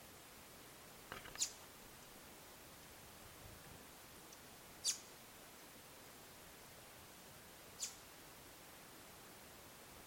White-browed Brushfinch (Arremon torquatus)
Llamado de contacto
Life Stage: Adult
Location or protected area: Parque Nacional Calilegua
Condition: Wild
Cerquero-Vientre-Blanco-Llamado.mp3